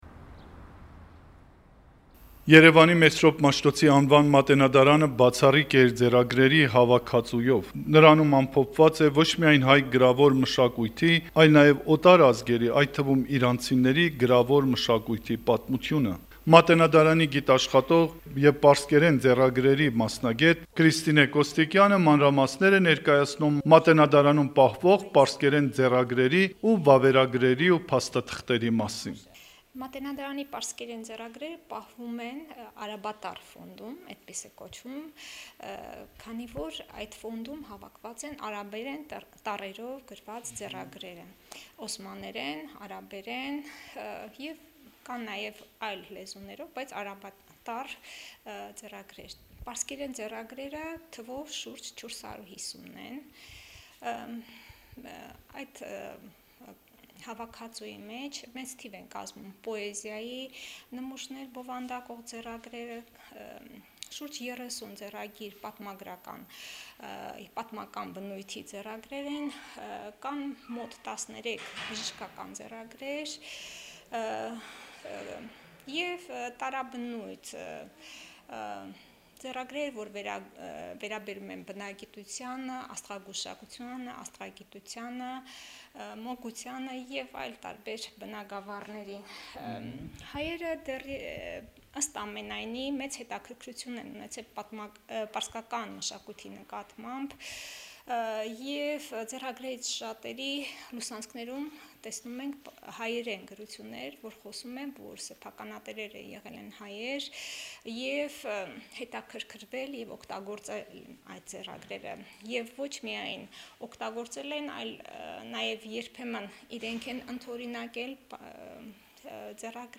Հարցազրույց՝ Մատենադարանի գիտաշխատող